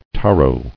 [ta·ro]